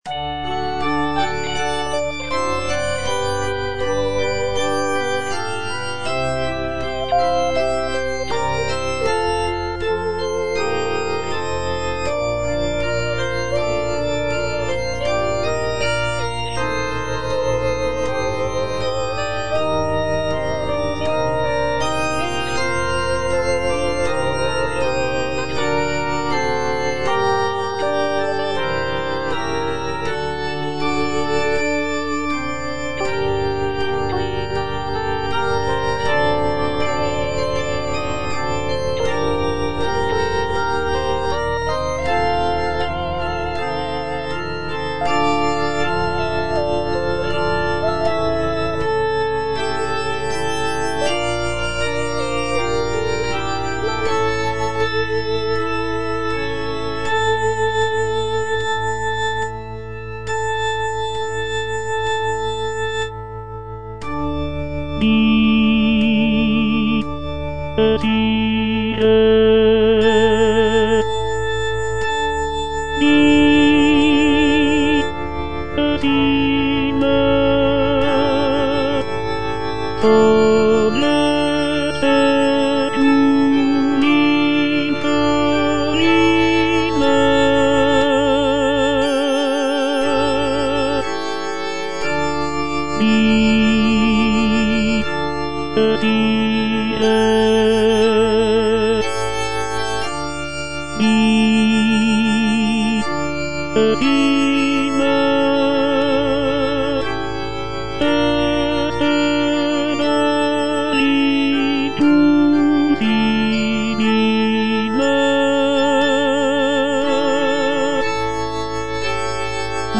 Tenor (Voice with metronome) Ads stop
is a sacred choral work rooted in his Christian faith.